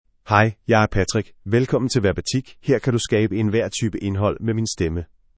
MaleDanish (Denmark)
Patrick — Male Danish AI voice
Patrick is a male AI voice for Danish (Denmark).
Voice sample
Listen to Patrick's male Danish voice.
Patrick delivers clear pronunciation with authentic Denmark Danish intonation, making your content sound professionally produced.